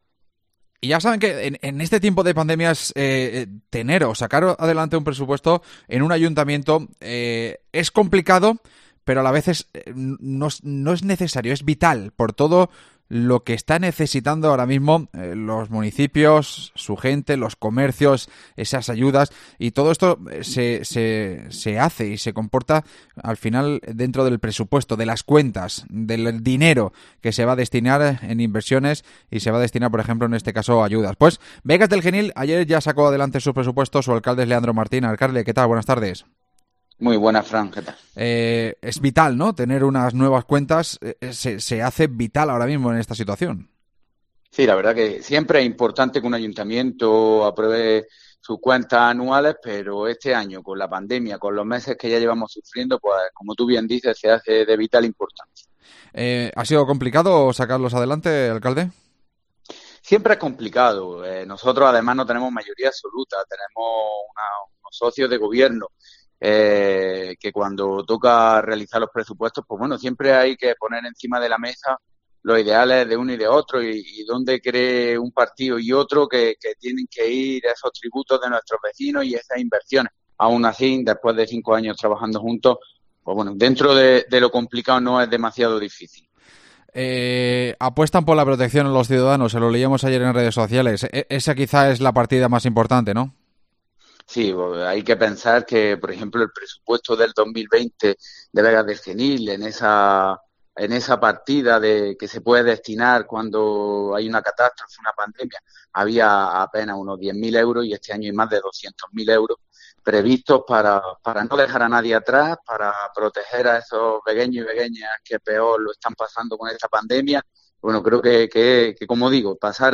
AUDIO: Hablamos con su alcalde, Leandro Martín